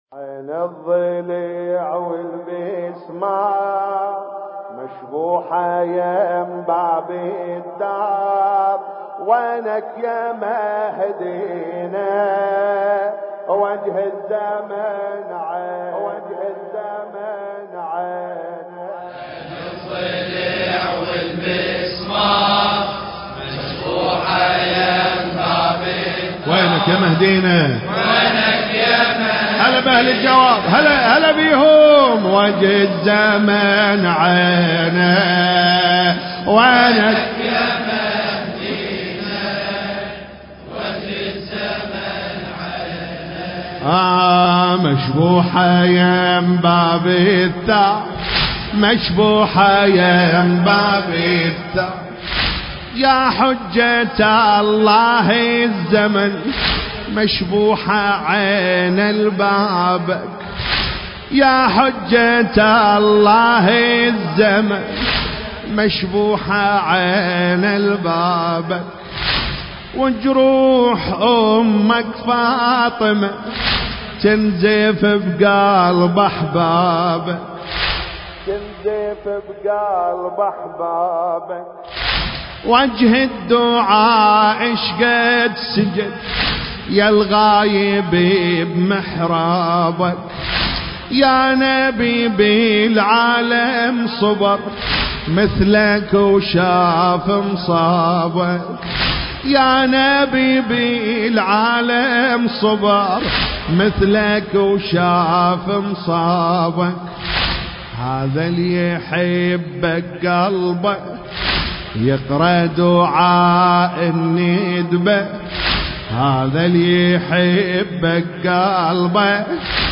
ليالي أستشهاد الزهراء (عليها السلام) ١٤٣٩ هـ